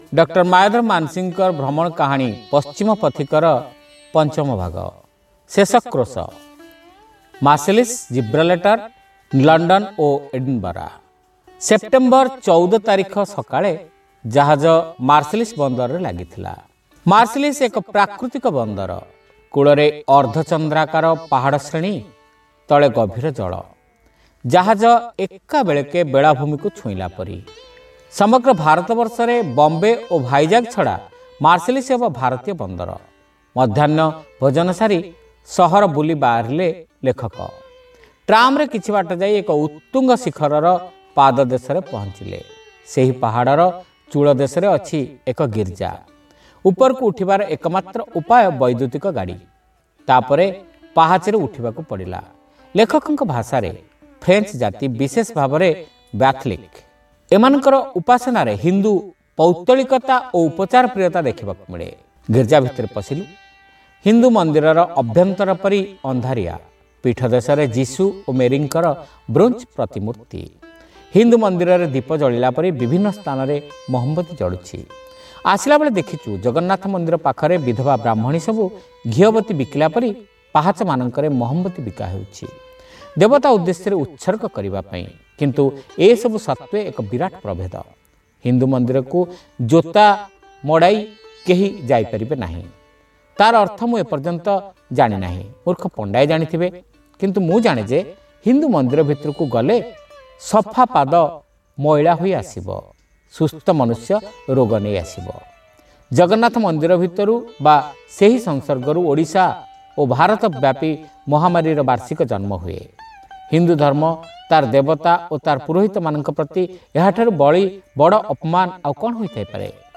Audio Story : Paschima Pathika ra Sampadita Rupa 5